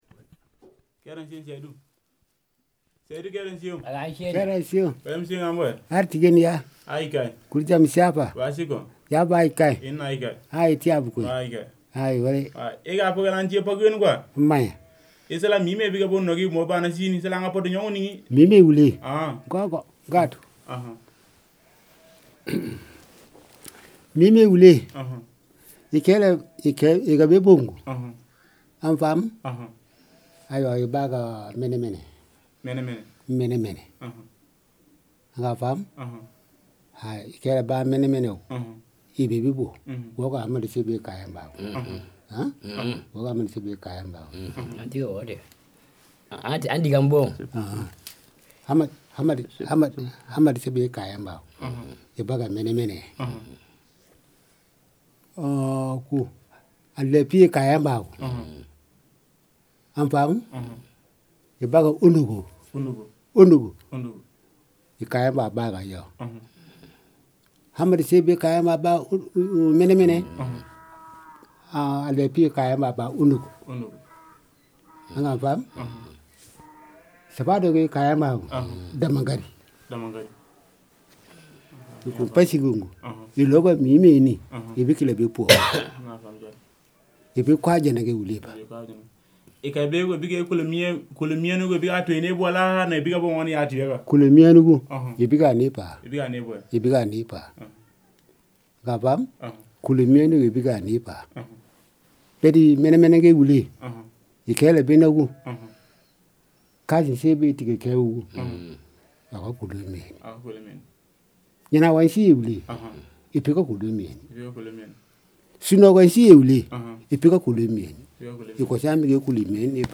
• field recordings in mp3 format made with digital microphone in Namagué village, Mali.